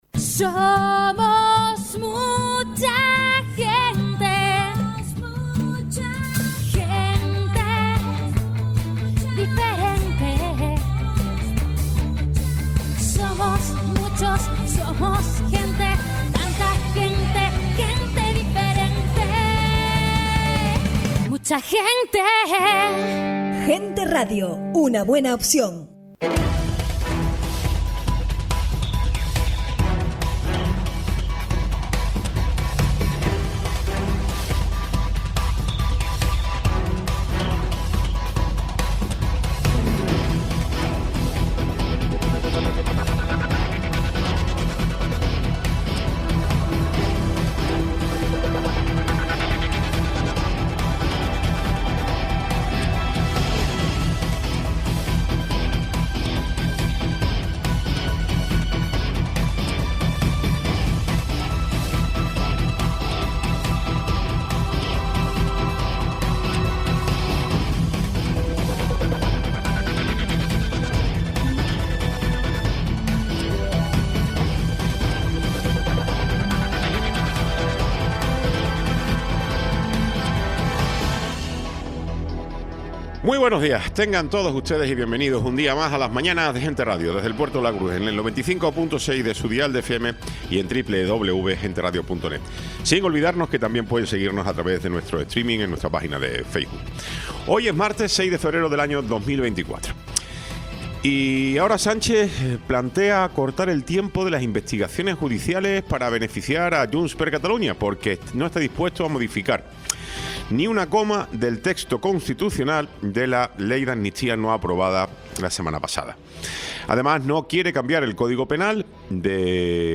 Tertulia
Programa sin cortes